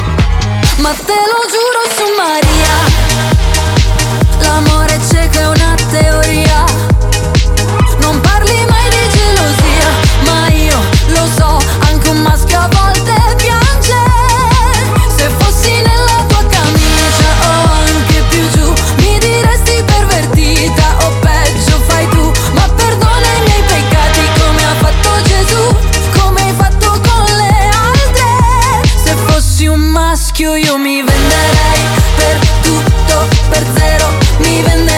2025-05-08 Жанр: Поп музыка Длительность